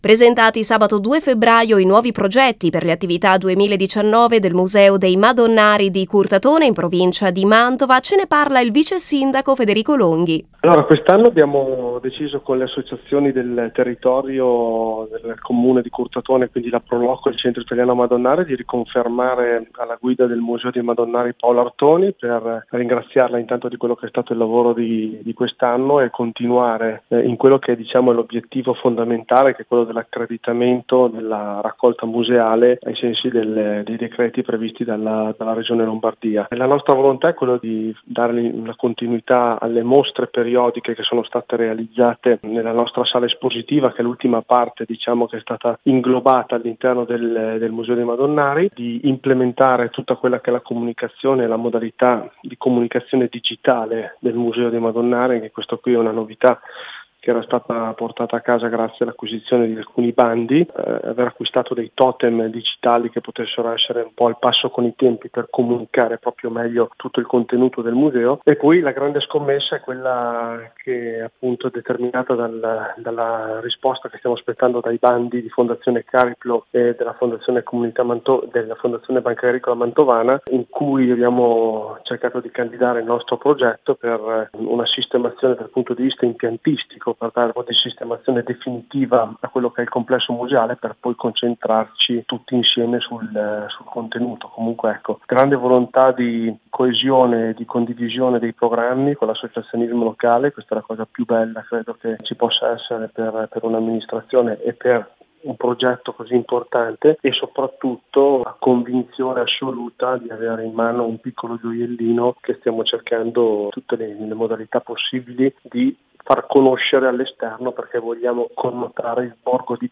Attività e progetti messi in campo dalla sinergia tra il museo, la Proloco, il Comune e il Centro Italiano Madonnari, come spiegato dal vicesindaco Federico Longhi: